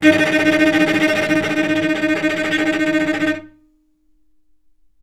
healing-soundscapes/Sound Banks/HSS_OP_Pack/Strings/cello/tremolo/vc_trm-D#4-mf.aif at b3491bb4d8ce6d21e289ff40adc3c6f654cc89a0
vc_trm-D#4-mf.aif